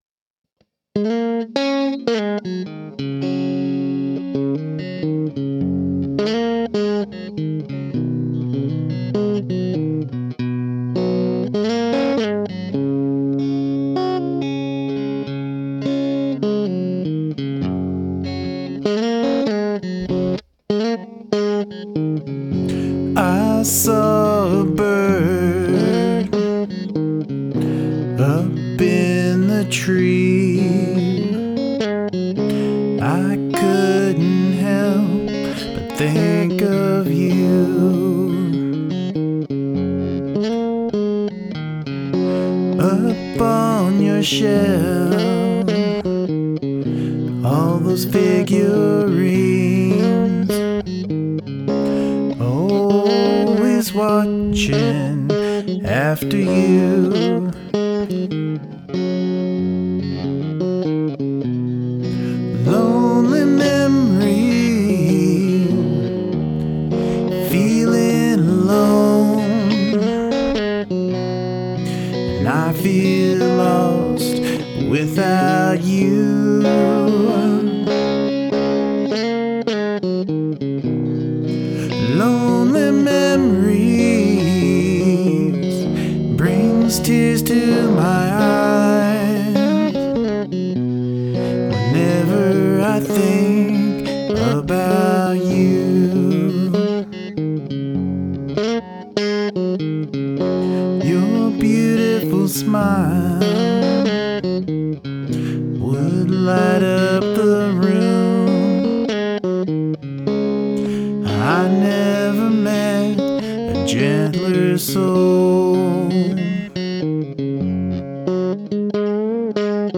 Tender and sweet.
A gentle sound and melodic, beautiful tune!